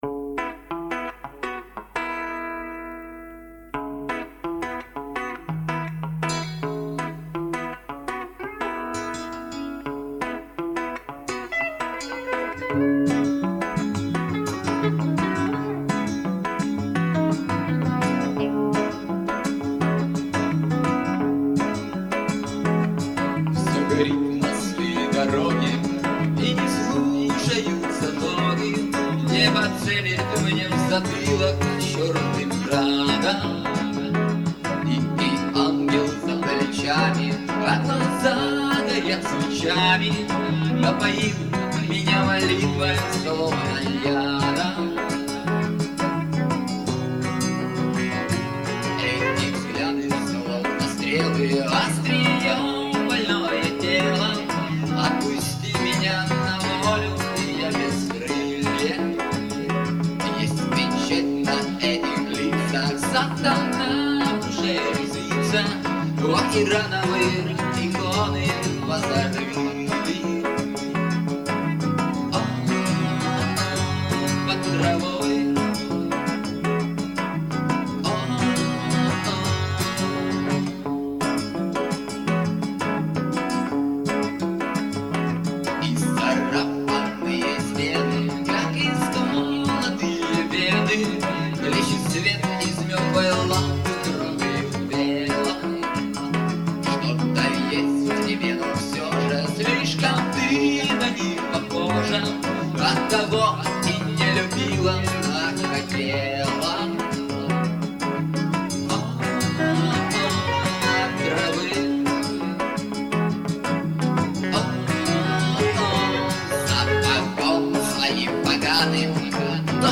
Семипалатинск, реставрированные записи - mp3.